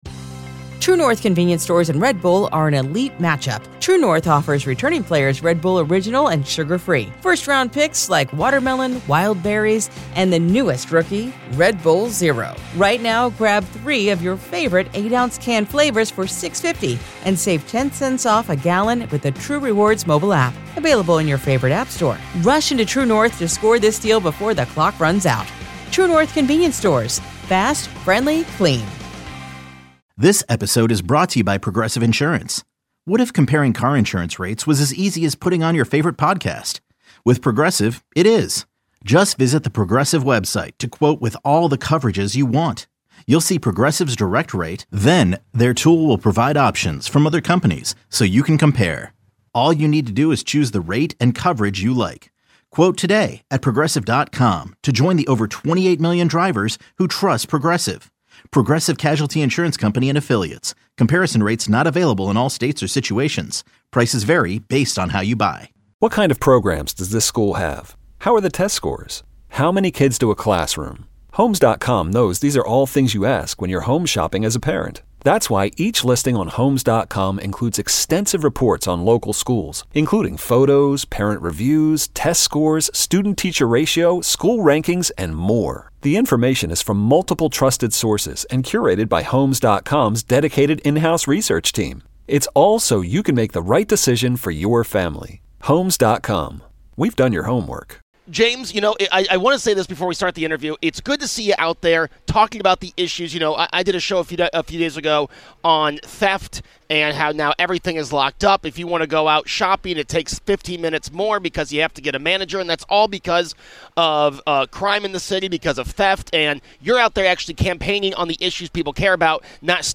broadcasts live from the Erie County Fair.